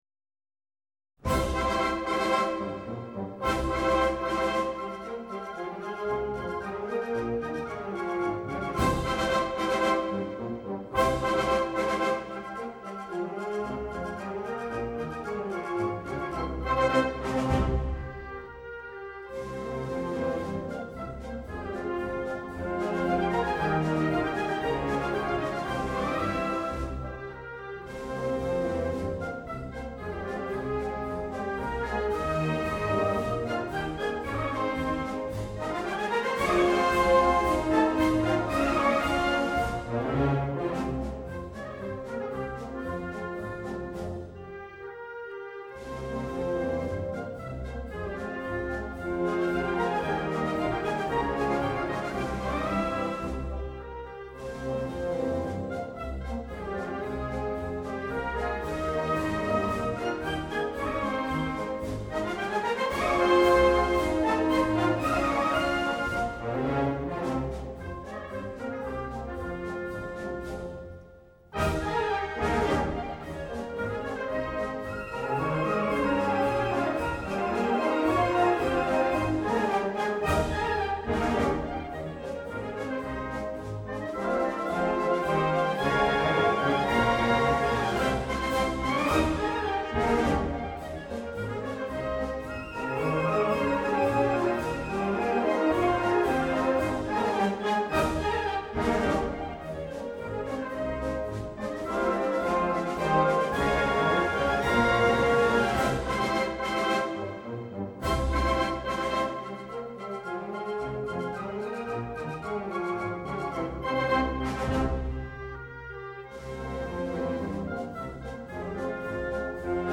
Pasodoble torero
banda / piano
Banda Sinfónica Municipal de Madrid, director Enrique García Asensio (RTVE Música, 2002)
la alternativa de dominguín [pasodoble torero].mp3